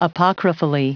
Prononciation du mot apocryphally en anglais (fichier audio)
Prononciation du mot : apocryphally